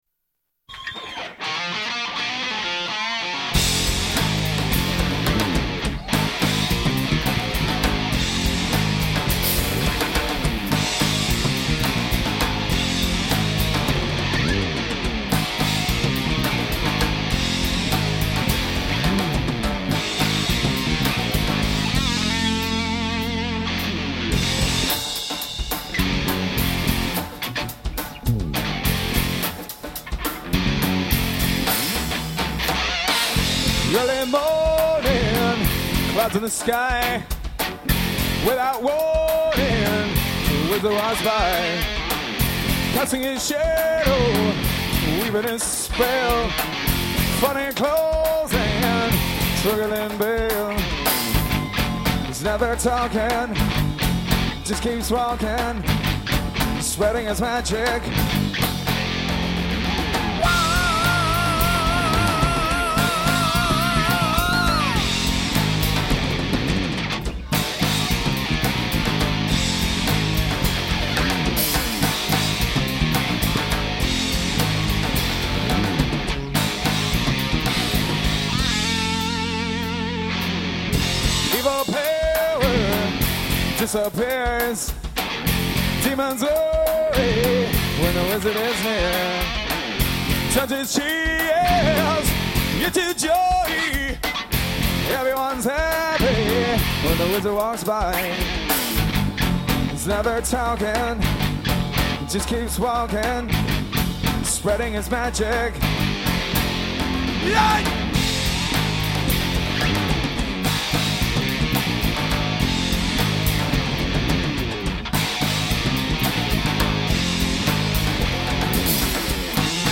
live in Whitehorse